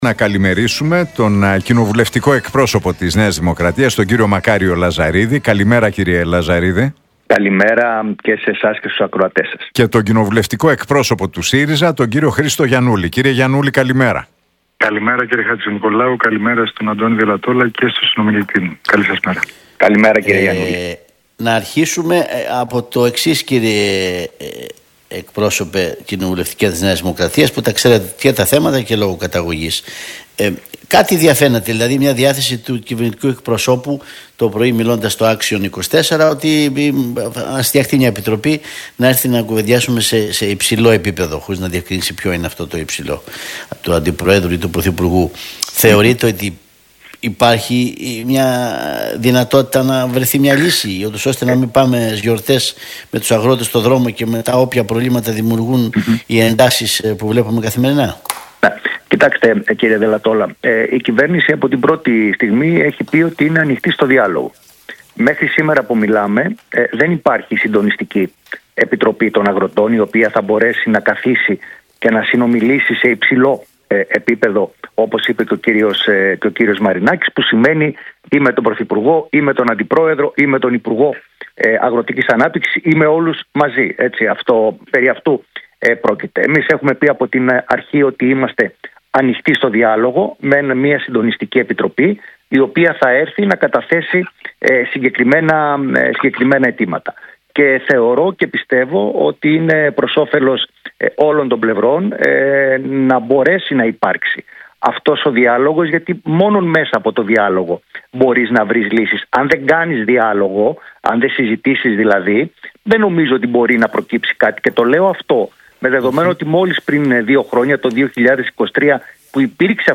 Debate Λαζαρίδη – Γιαννούλη στον Realfm 97,8 για τις αγροτικές κινητοποιήσεις και την ακρίβεια